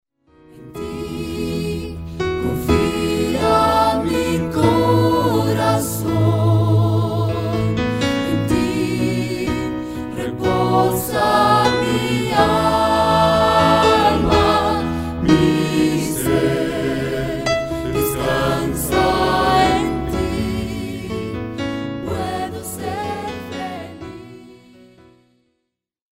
álbum clásico de adoración